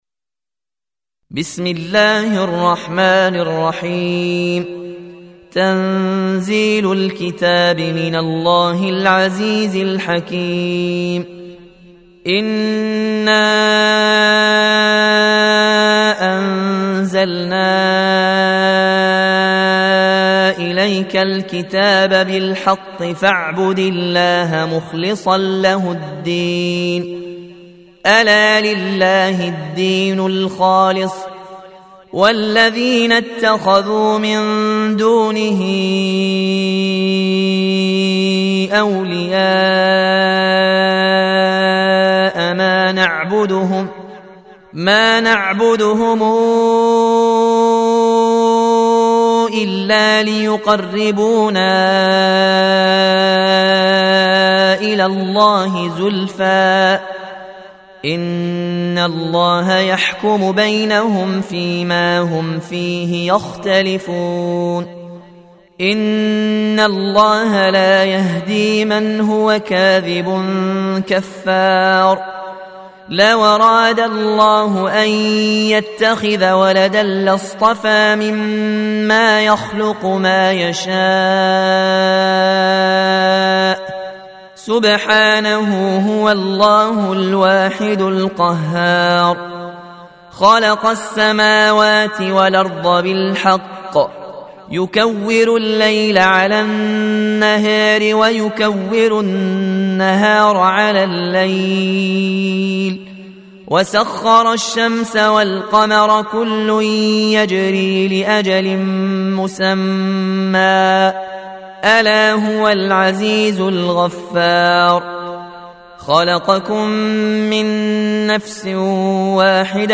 39. Surah Az-Zumar سورة الزمر Audio Quran Tarteel Recitation
Surah Sequence تتابع السورة Download Surah حمّل السورة Reciting Murattalah Audio for 39. Surah Az-Zumar سورة الزمر N.B *Surah Includes Al-Basmalah Reciters Sequents تتابع التلاوات Reciters Repeats تكرار التلاوات